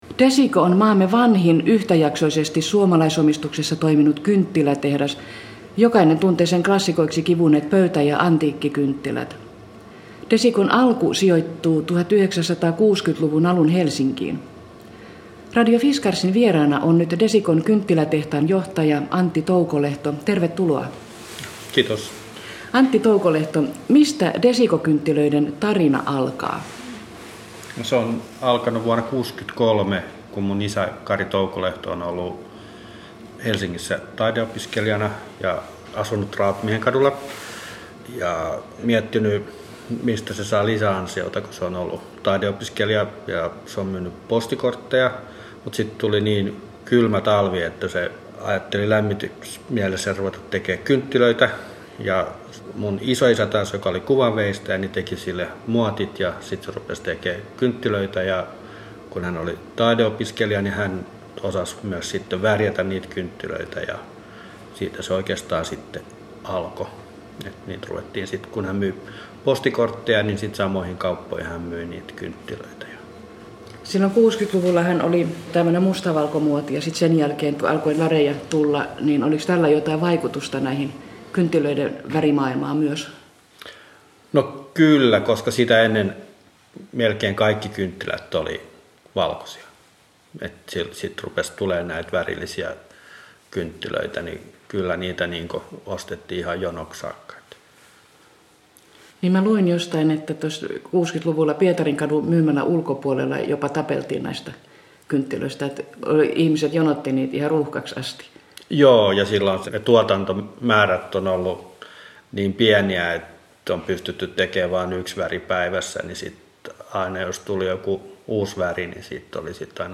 Miten Kuparipajassa valmistaudutaan tulevaan matkailukauteen? Haastattelun lopussa kuullaan yrittäjien Veitsitehdasta koskevista suunnitelmista.